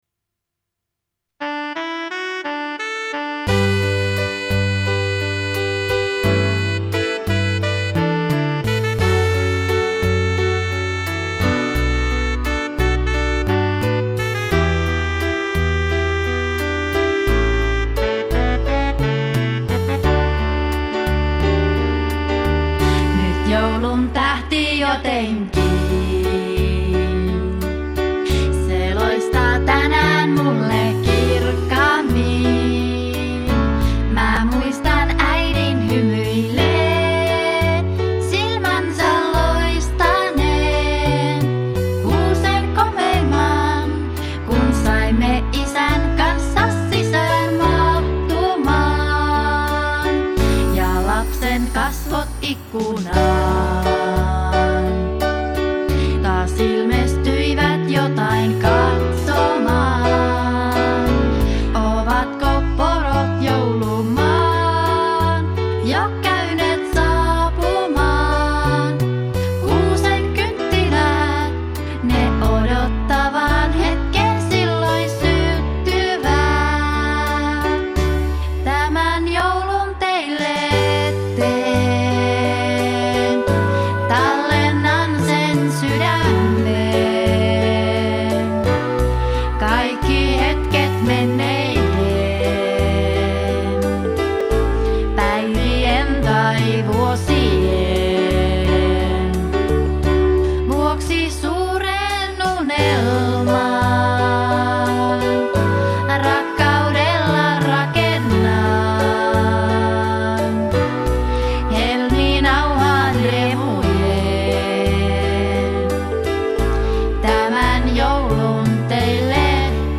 laulu
instrumenttien ohjelmointi
Äänitys on tehty kotistudiossa Kemissä.